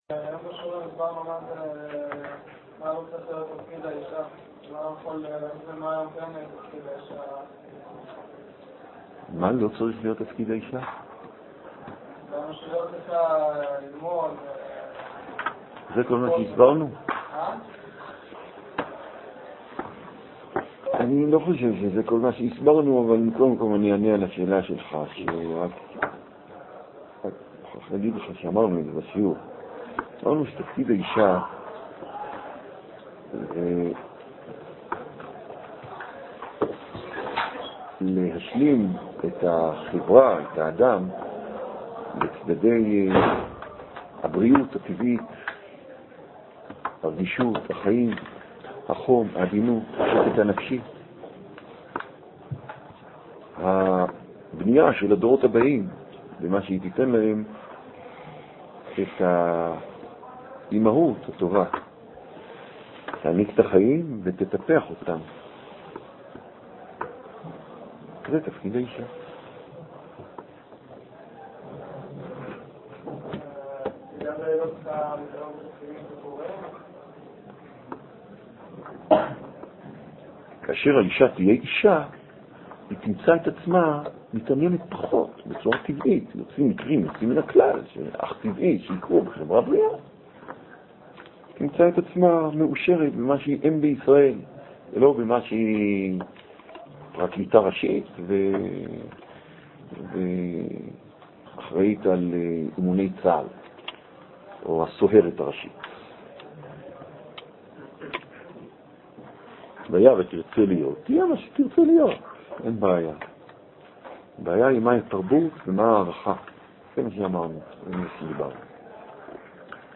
מתוך שו"ת. ניתן לשלוח שאלות בדוא"ל לרב